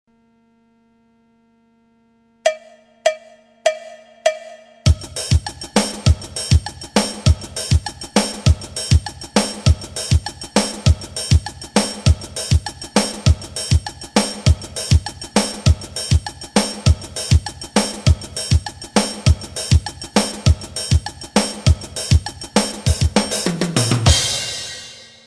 Il se joue quant à lui d'une manière assez rapide avec triangle, zabumba, shaker.
Section rythmique du baião
Les losanges du haut de la partition représentent le triangle que l'on retrouvera souvent dans les musiques du nordeste, losange noir = triangle fermé, losange blanc = triangle ouvert. les triangles en mi et en sol représentent des cloches en bois graves et aigues. La grosse caisse qui imite le surdo ou la zabumba est la note du bas (la ) que je vous invite à bien observer car elle fait une syncope très caractéristique du baião.